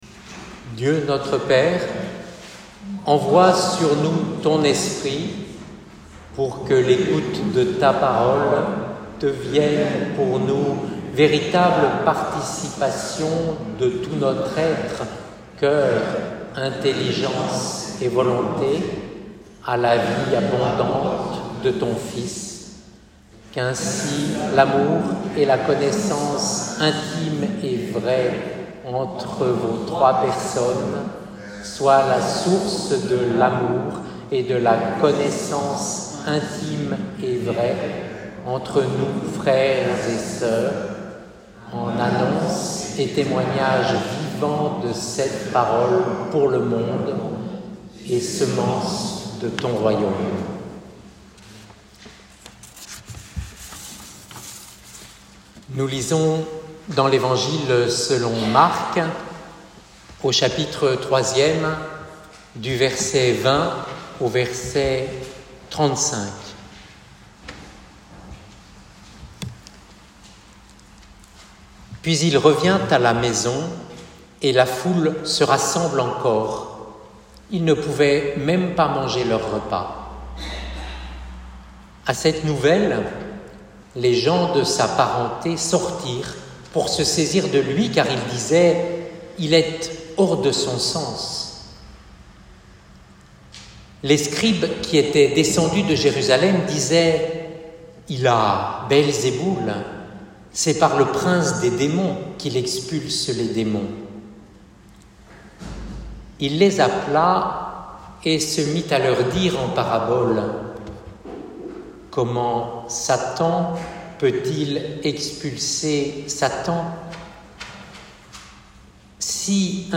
Prédication culte 9 juin 2024.pdf